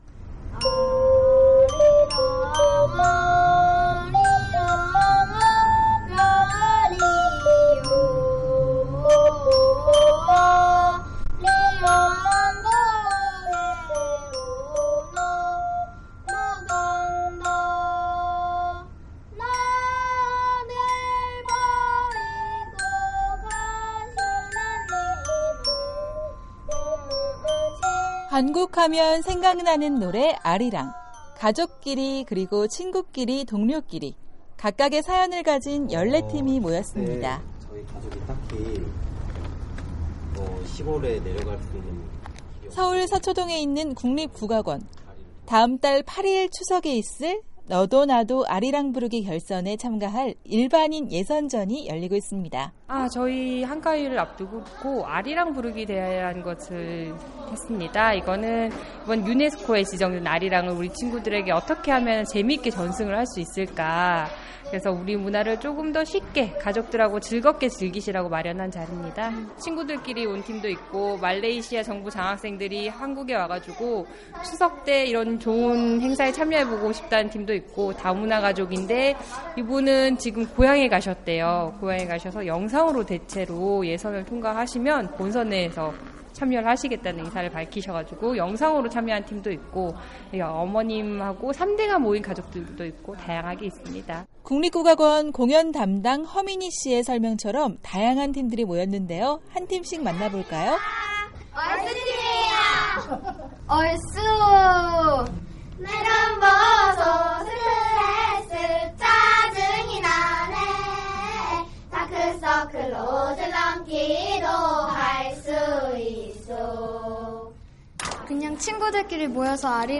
기자가 전해드립니다